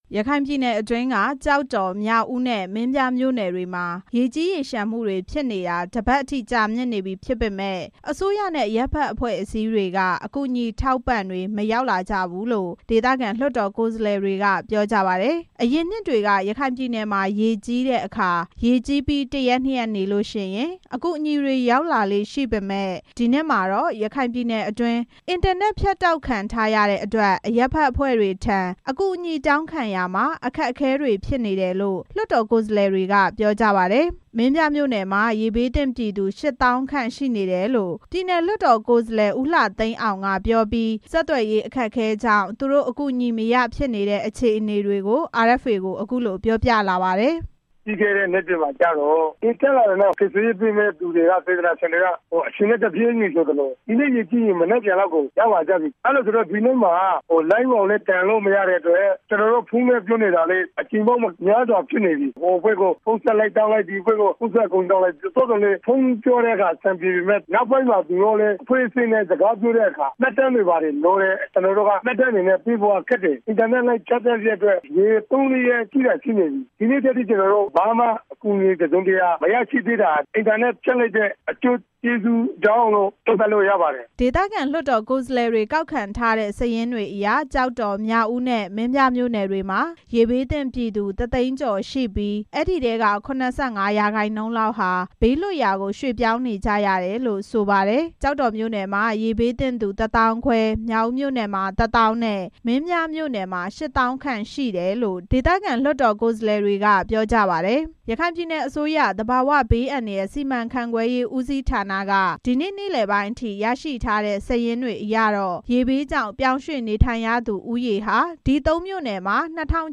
မင်းပြားမြို့နယ်မှာ ရေဘေးသင့်ပြည်သူ ရှစ်သောင်းခန့်ရှိနေတယ်လို့ ပြည်နယ်လွှတ်တော် ကိုယ်စားလှယ် ဦးလှသိန်းအောင်က ပြောပြီး ဆက်သွယ်ရေး အခက်အခဲကြောင့် သူတို့အကူအညီ မရဖြစ်နေတဲ့ အခြေအနေတွေကို RFA ကို အခုလို ပြောပြလာပါတယ်။
ကျောက်တော်မြို့နယ် ပြည်နယ်လွှတ်တော် ကိုယ်စားလှယ် ဦးမောင်သန်းစိန်ကတော့ အစိုးရထံ အကူအညီပေးဖို့ တောင်းခံထားအကြောင်း အခုလို ပြောပါတယ်။